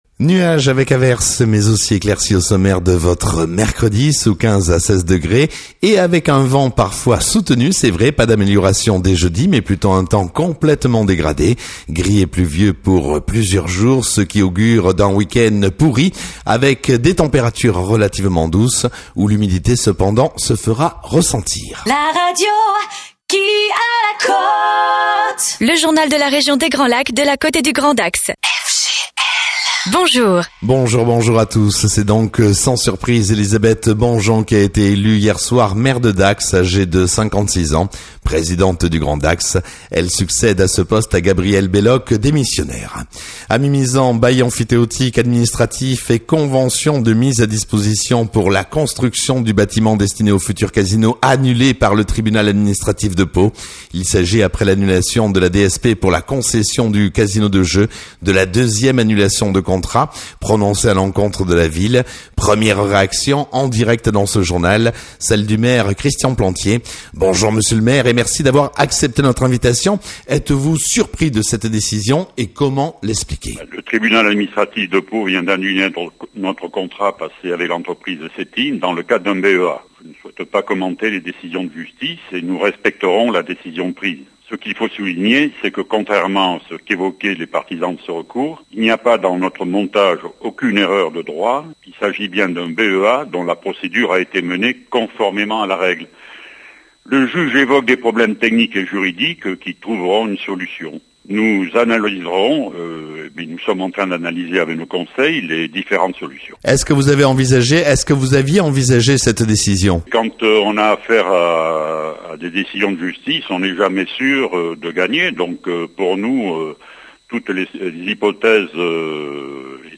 Le 3 novembre 2016, le Tribunal administratif de Pau a annulé le contrat de Bail emphytéotique administratif (BEA) pour la construction du casino aux Hournails, à l’entrée de Mimizan Plage. Le maire de Mimizan Christian Plantier et Xavier Fortinon ont réagi à ce jugement sur les ondes de la radio Fréquence Grands Lacs.
(Re)découvrez l’intervention de Christian Plantier dans le journal du 8 novembre (à 1 min 06) :